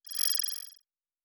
pgs/Assets/Audio/Sci-Fi Sounds/Interface/Data 11.wav at 7452e70b8c5ad2f7daae623e1a952eb18c9caab4